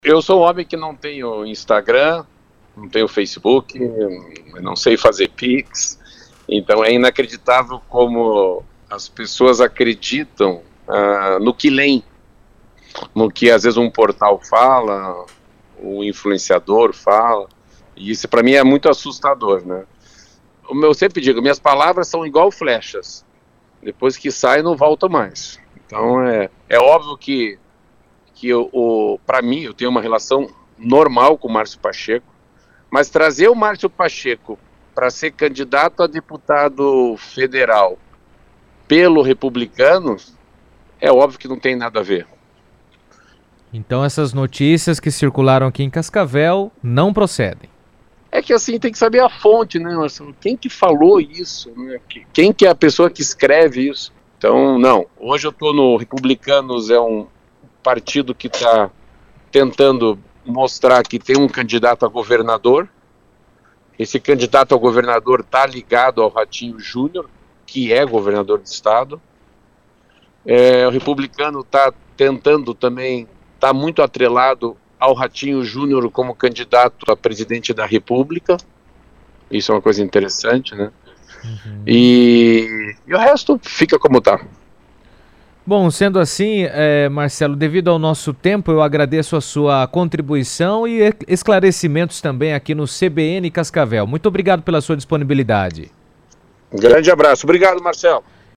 Em entrevista ao vivo para a CBN Cascavel